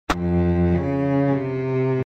treasureCheatOpenEmpty.mp3